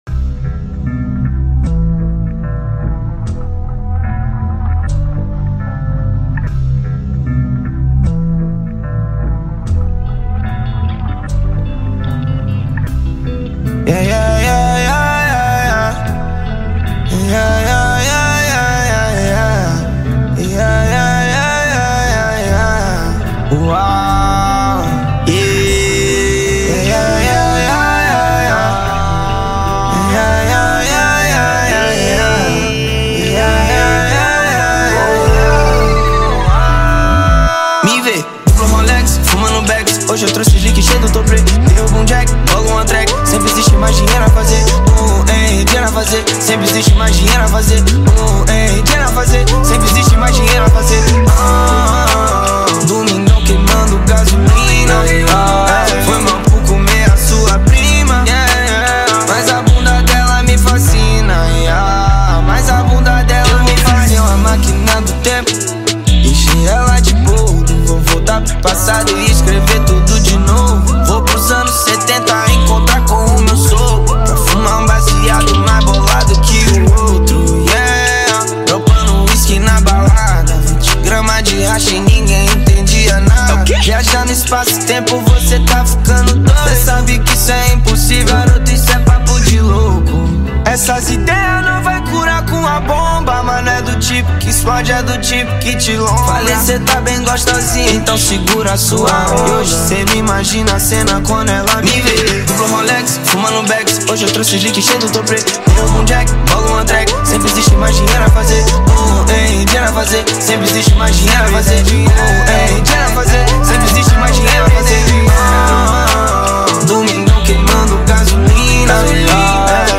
2024-04-08 19:05:42 Gênero: Trap Views